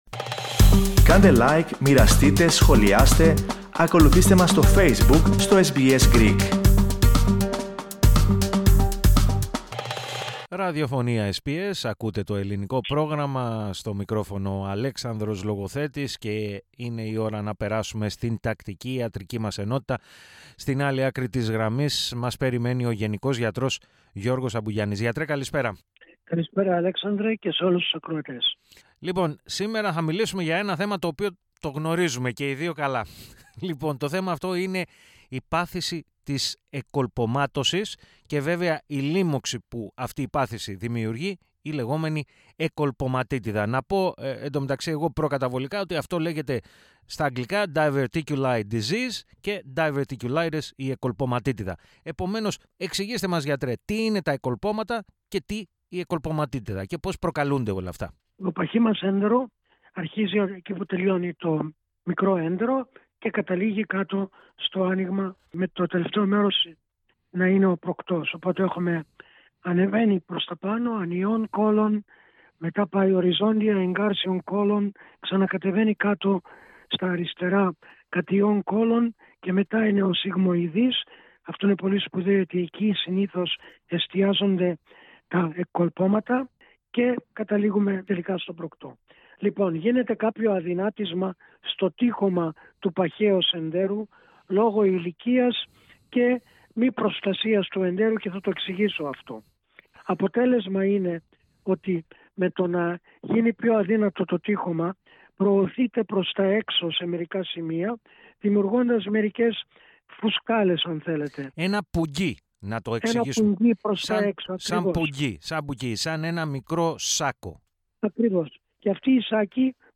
Για περισσότερες, όμως, πληροφορίες σχετικά με αυτήν την πάθηση και την αντιμετώπιση της, ακούστε ολόκληρη τη συνέντευξη.